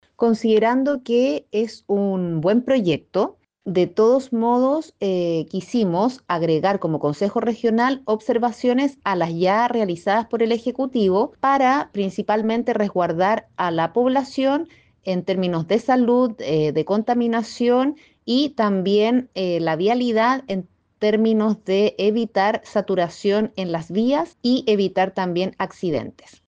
Por su parte, la consejera regional, Paula Rosso, comentó que buscan agregar observaciones -a las que ya realizadas por el Ejecutivo- con el afán de resguardar a la población en términos de salud, de contaminación y evitar la saturación en las vías.